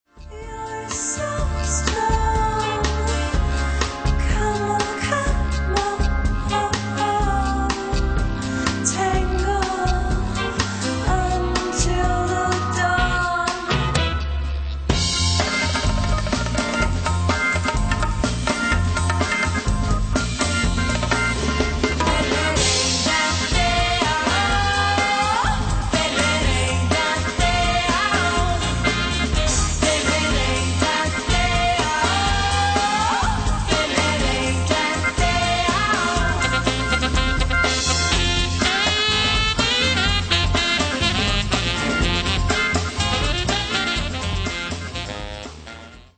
Lounge-Jazz-Combo